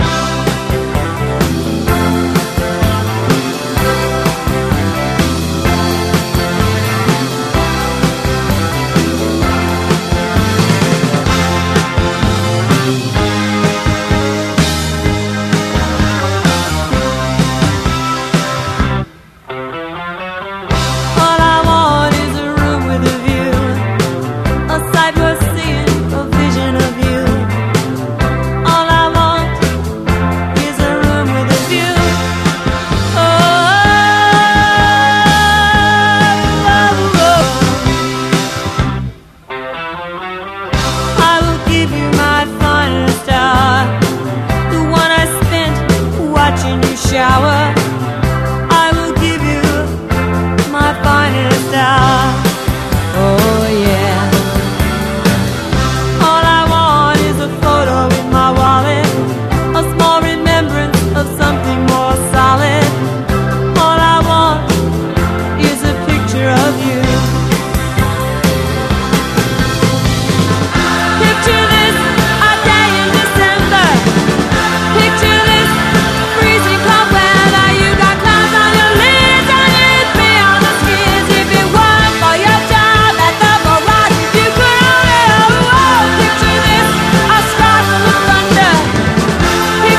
ROCK / 80'S/NEW WAVE. / NEW WAVE / OLD SCHOOL